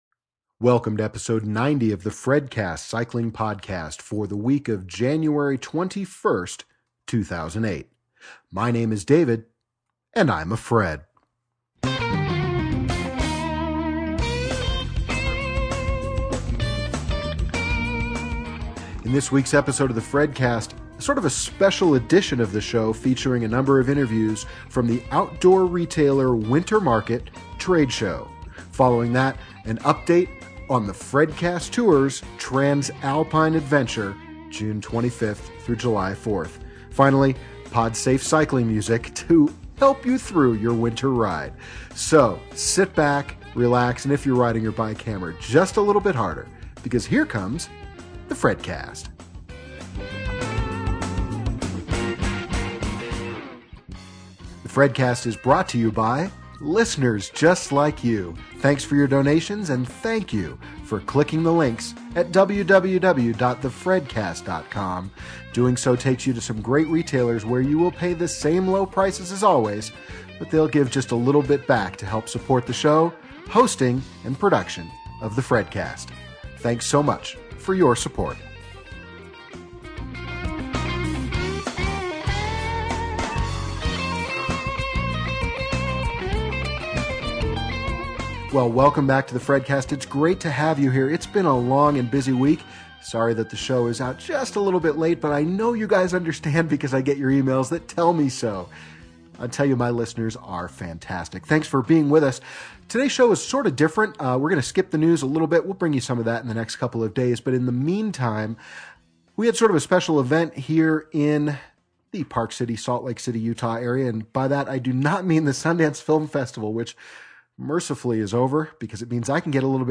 Interview: GoPro HelmetCams Interview: Spot Satellite Messenger